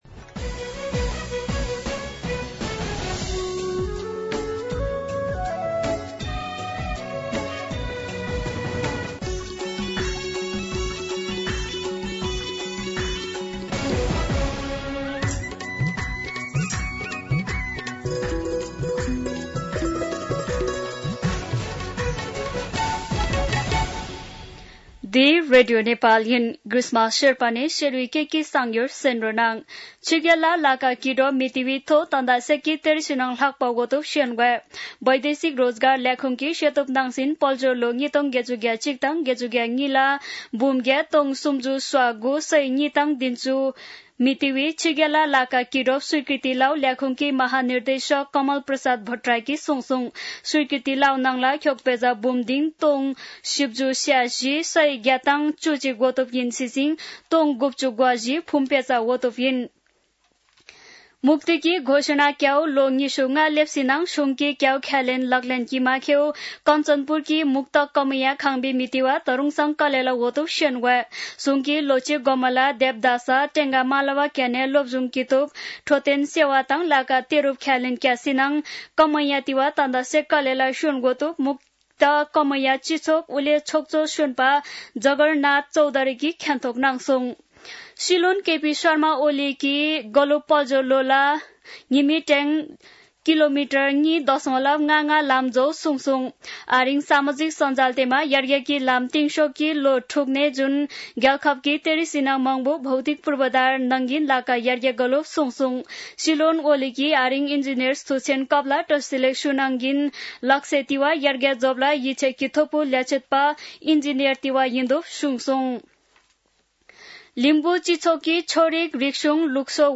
शेर्पा भाषाको समाचार : ३ साउन , २०८२